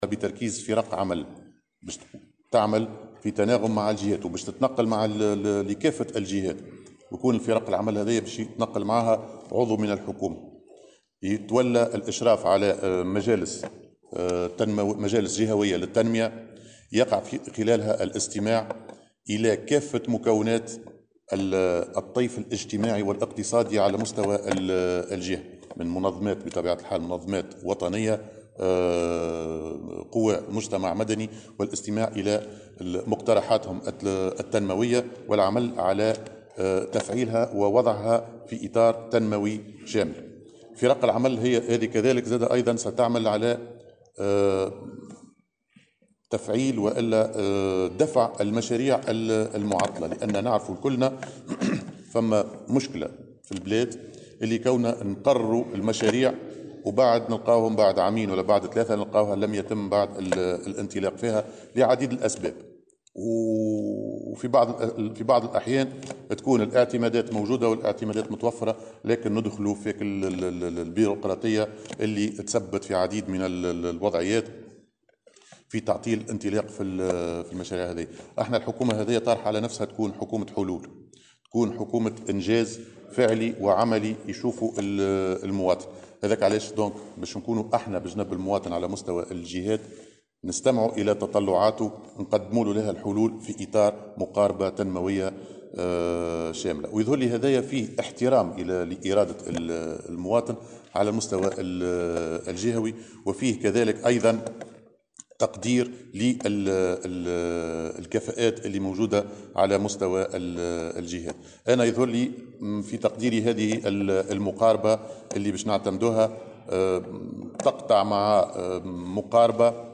وأضاف اليوم على هامش إشرافه على ندوة الولاة بثكنة العوينة، أن هذه الفرق ستعمل أيضا على دفع المشاريع المعطلة في الجهات وتجاوز الإشكاليات التي تحول دون تنفيذها.